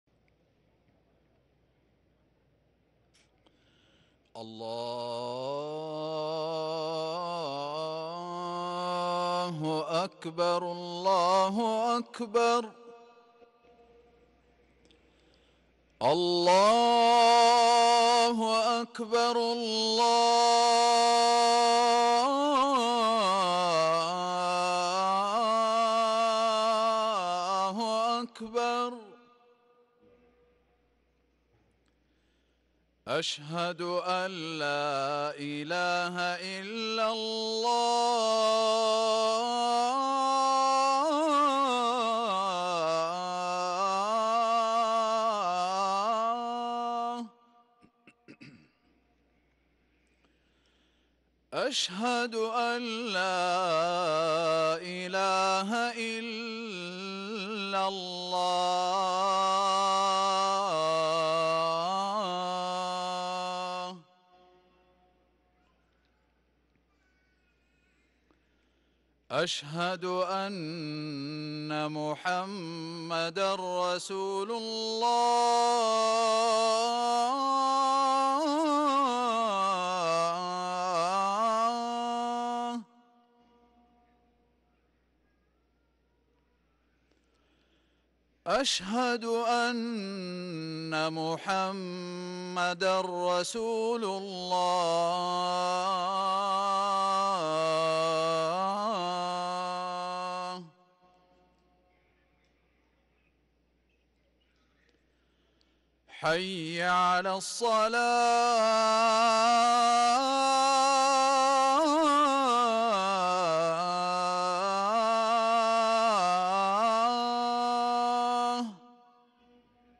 اذان الفجر